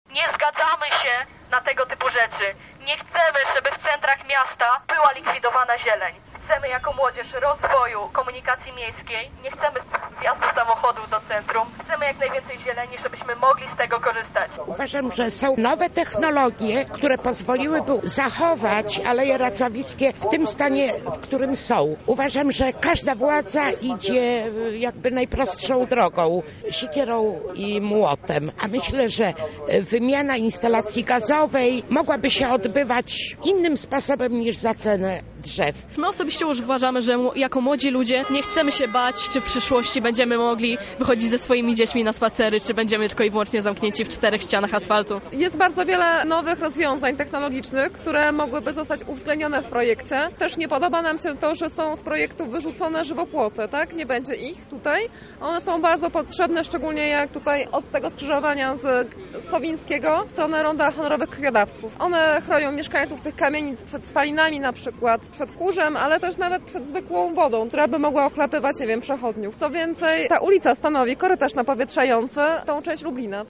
W środę, 29 marca o godzinie 16:00 na mieście protestowali ci, którzy sprzeciwiają się wycince drzew w okolicach Racławickich. Na miejscu była nasza reporterka:
protest.mp3